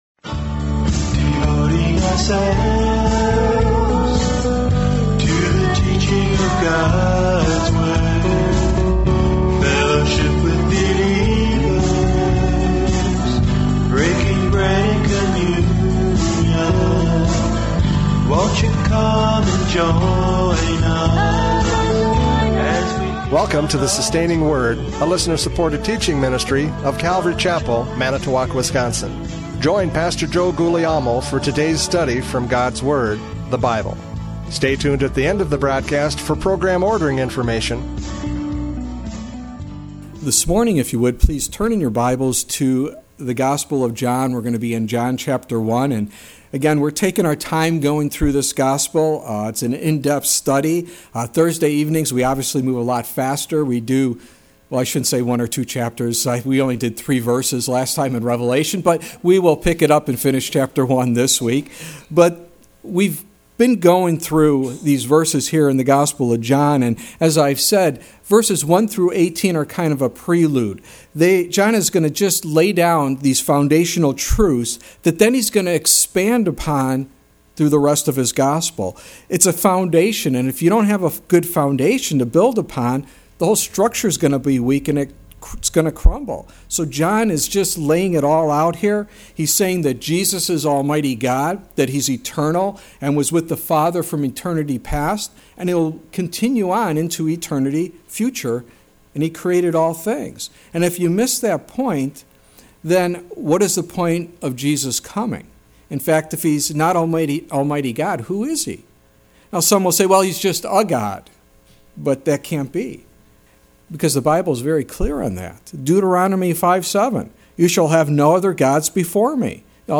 John 1:6-13 Service Type: Radio Programs « John 1:4-5 The Battle of Light and Darkness!